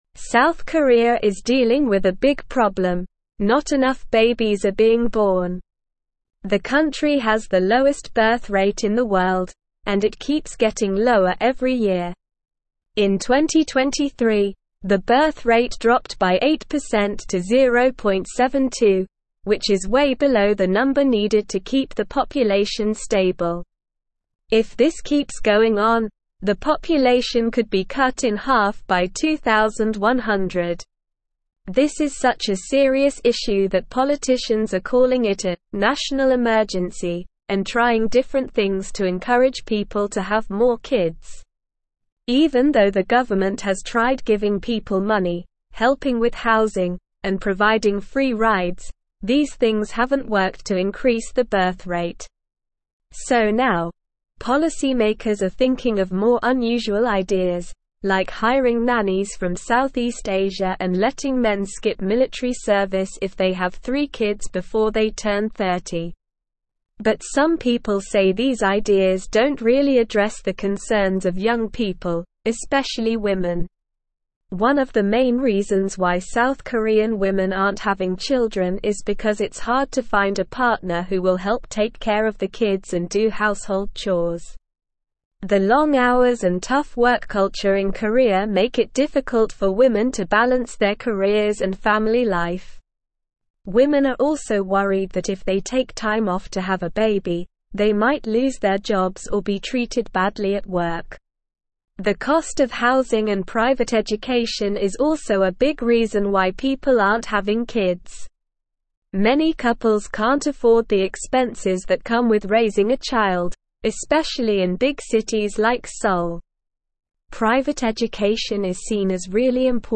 Slow
English-Newsroom-Upper-Intermediate-SLOW-Reading-South-Koreas-Birth-Rate-Crisis-Demographic-Decline-Looms.mp3